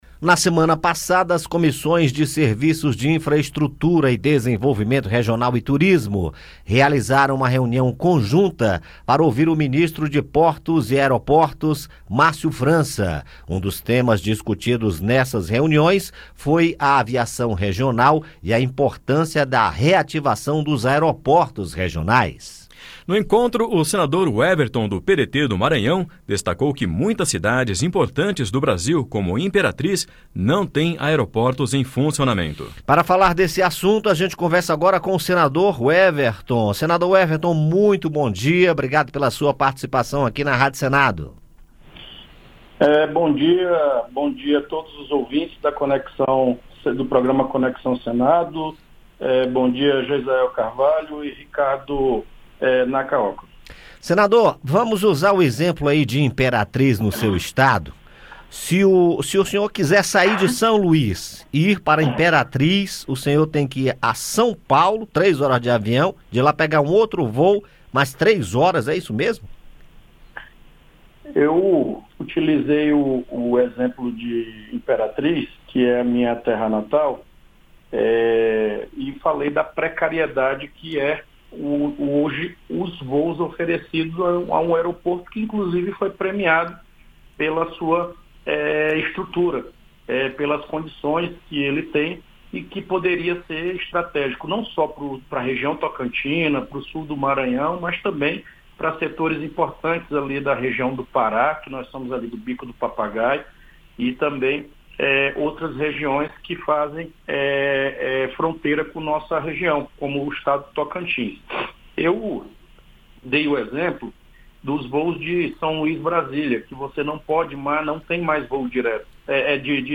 Entrevista: Senador Weverton Rocha comenta importância da reativação de aeroportos regionais